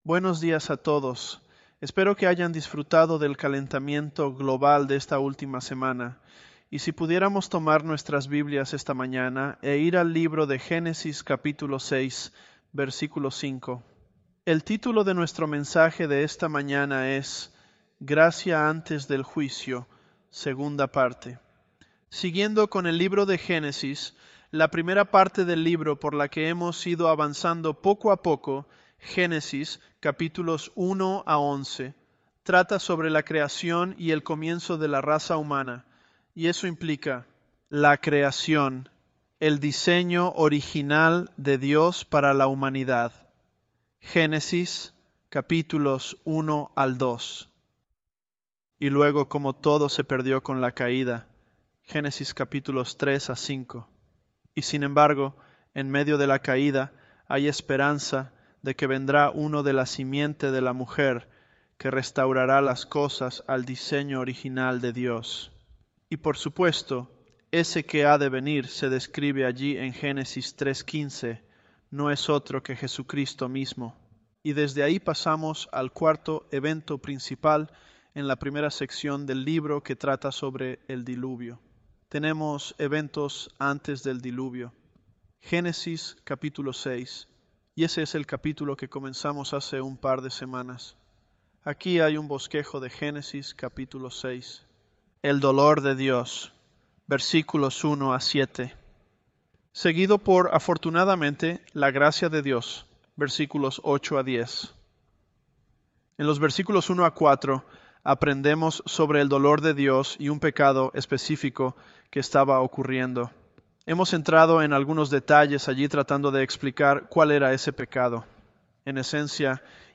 Sermons
ElevenLabs_Genesis-Spanish027b.mp3